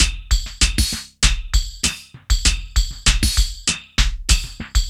98WAGONLP5-L.wav